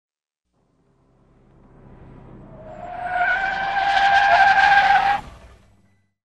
Звук резкого торможения машины
Звуки торможения с визгом шин, резкой остановки автомобиля на асфальте, на грунтовой дороге и на дороге, посыпанной гравием.
Категория : Звуки транспорта
Звук, когда машина подъезжает, резко тормозит с визгом шиш и останавливается